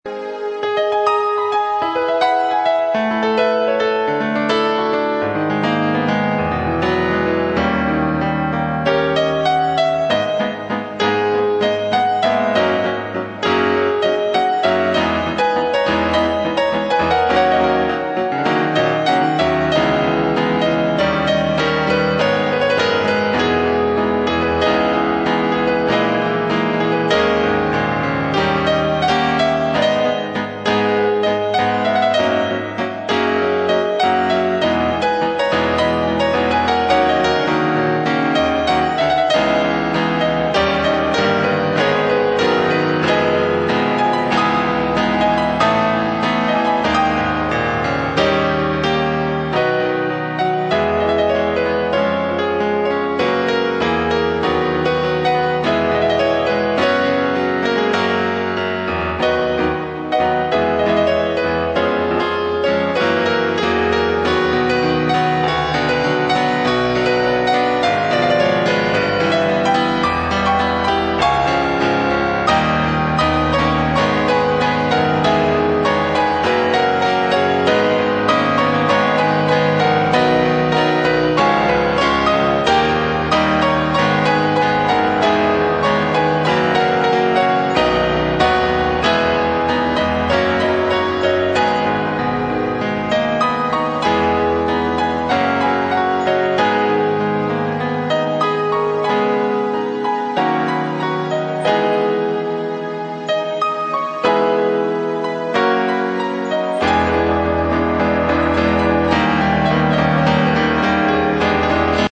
作編曲・演奏(Drums,Piano,Keyboards
[Bass,Guitar,etc])：